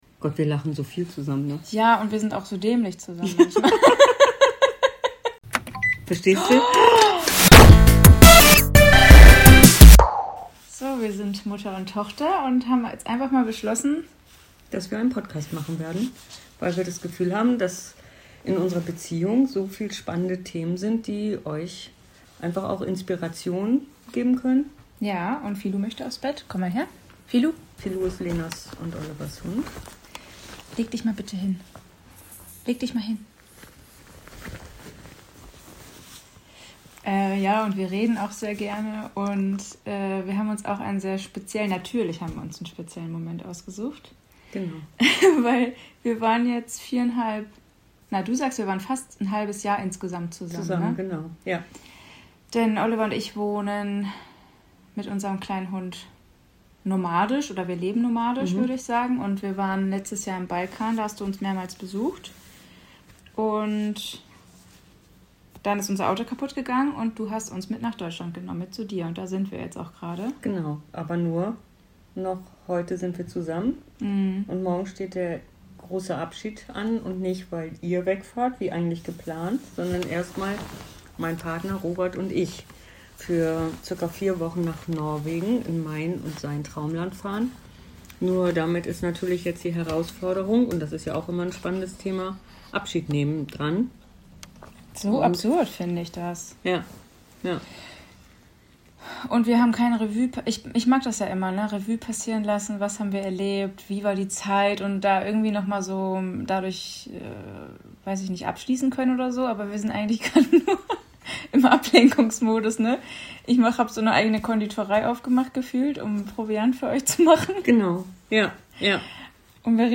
Mutter & Tochter sprechen ehrlich über Nähe, Konflikte und Neuanfänge.